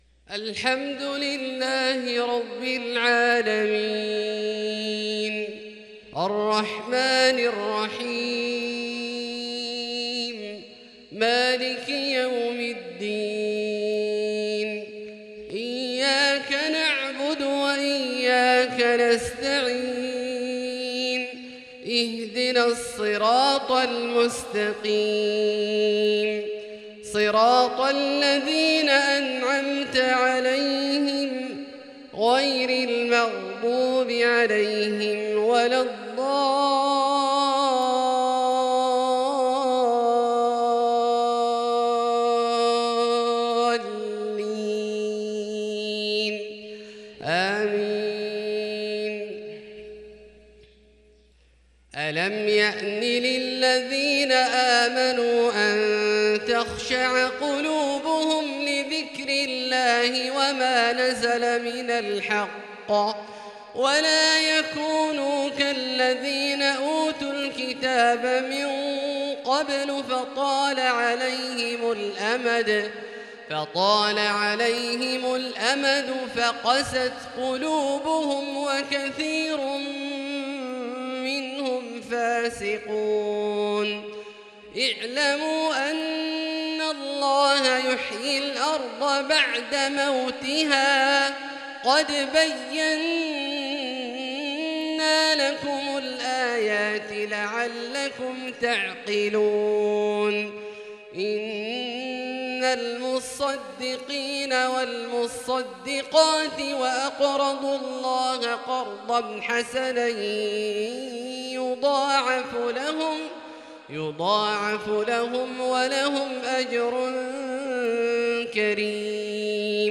عشائية ترنمية عذبة 😍 للشيخ عبدالله الجهني من سورة الحديد - 11 جمادى الآخرة 1444هـ